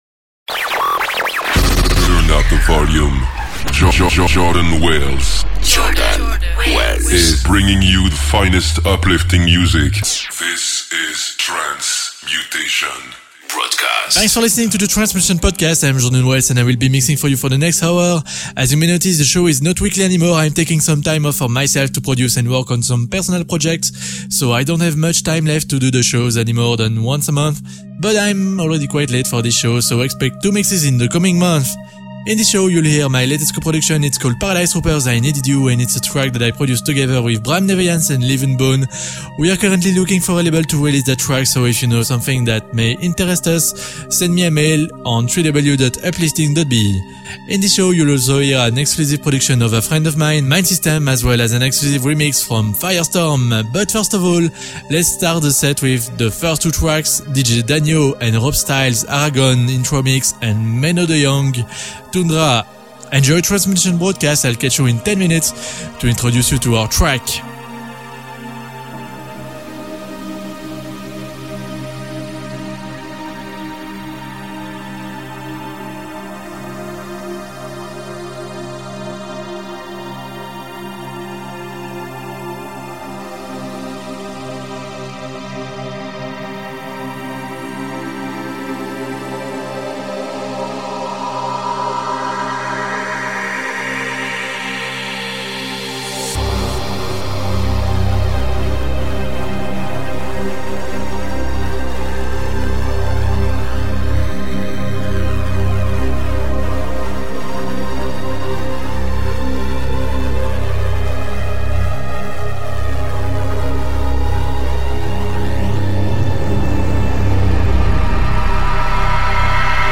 fresh unsigned trance tracks!
uplifting trance